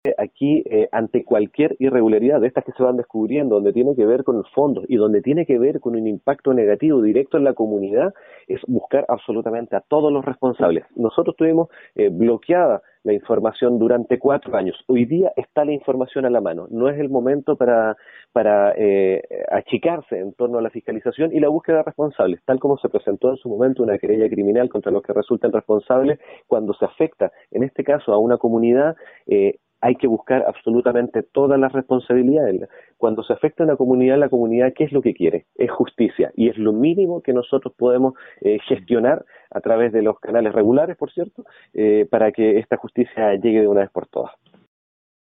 Sobre las acciones que debiera seguir el municipio ante la gravedad de lo descubierto, el concejal Rodolfo Norambuena dijo que no se deben escatimar esfuerzos al momento de buscar responsabilidades y deben presentarse los recursos que sean necesarios ante la justicia, porque se ha afectado a toda una comunidad.